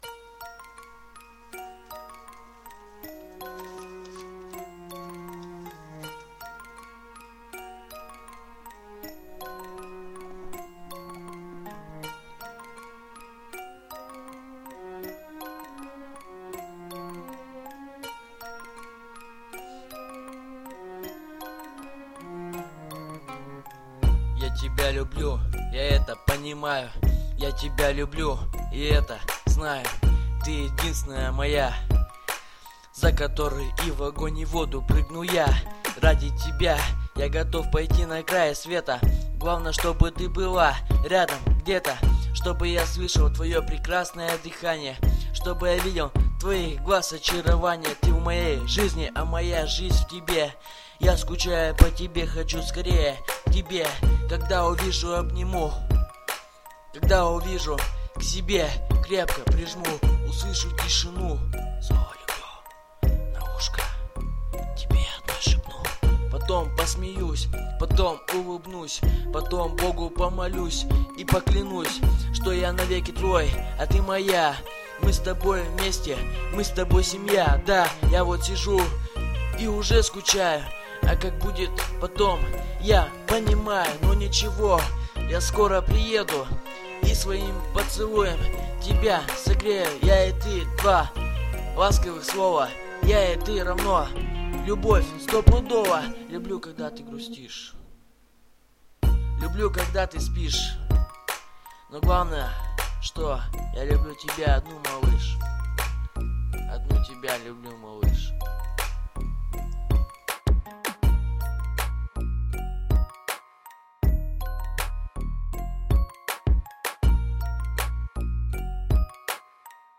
Любовный рэп